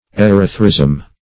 Search Result for " erythrism" : The Collaborative International Dictionary of English v.0.48: Erythrism \E*ryth"rism\, n. [Gr.